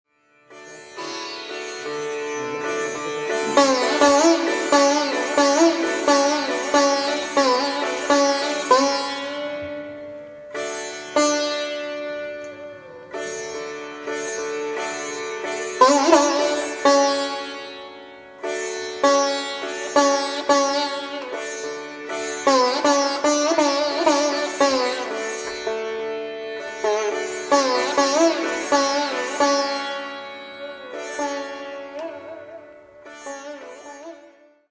Ситар
sitar.mp3